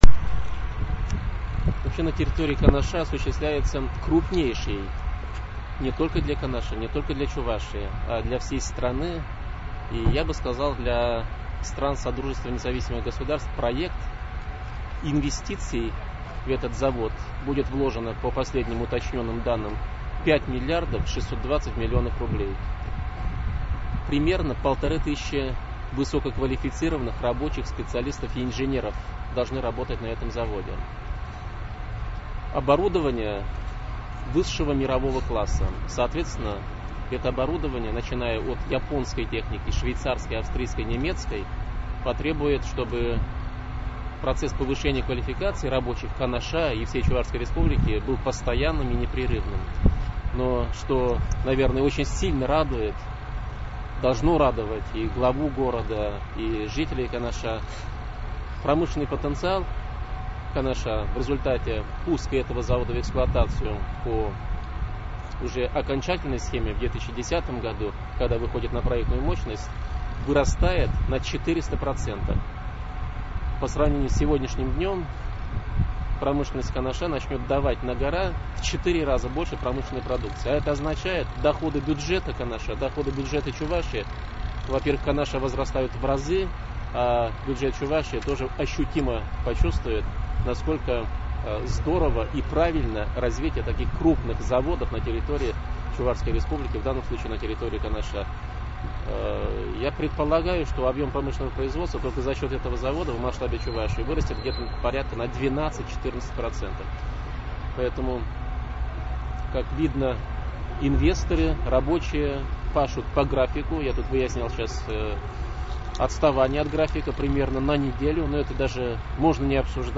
На встрече с журналистами глава республики сказал следующее:
interviu.mp3